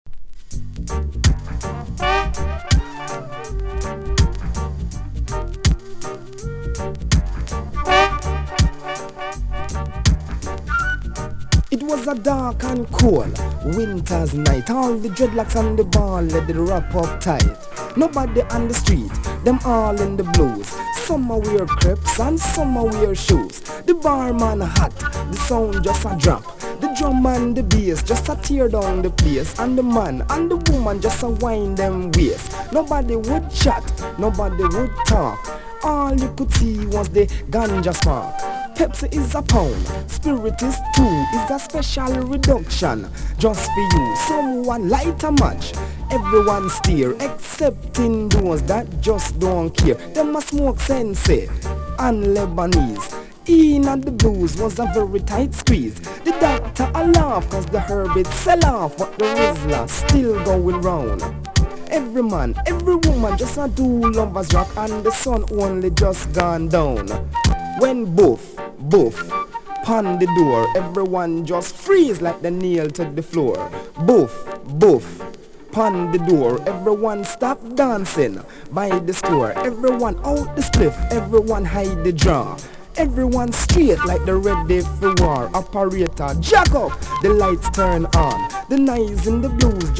• REGGAE-SKA
1. REGGAE >